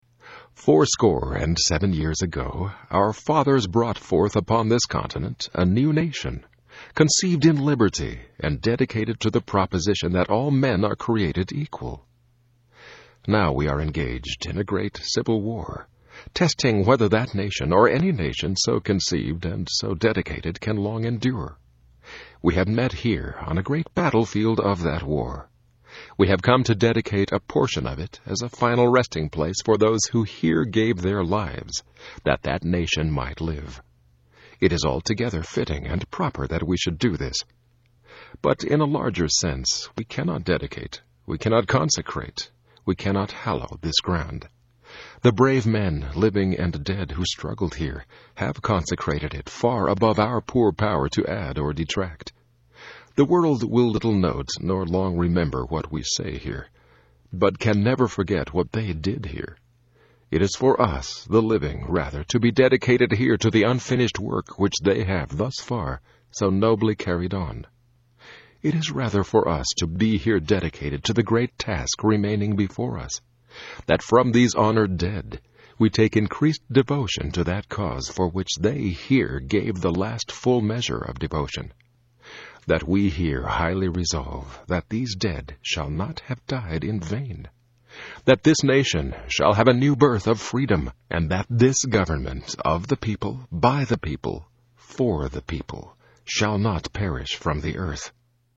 Another reading